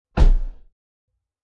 Thump